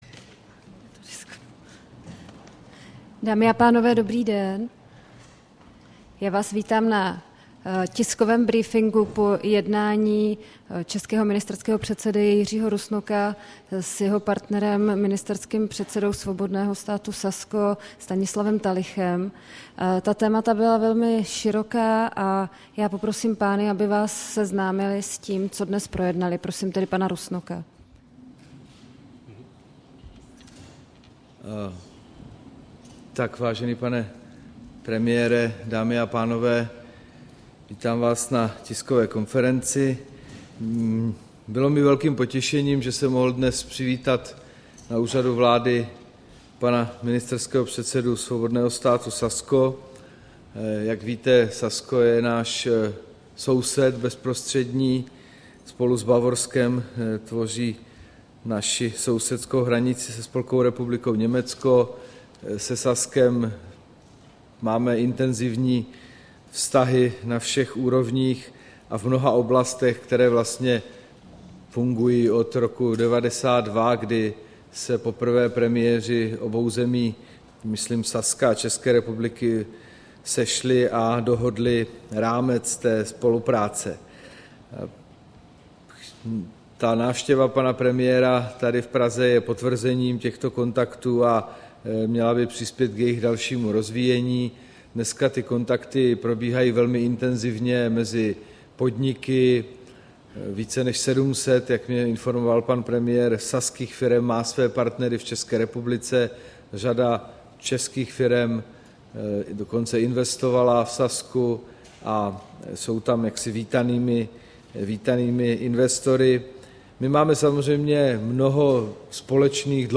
Tisková konference po setkání premiéra Jiřího Rusnoka s ministerským předsedou Saska Stanislawem Tillichem, 21. října 2013